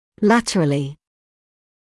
[‘lætərəlɪ][‘лэтэрэли]латерально, в сторону, вбок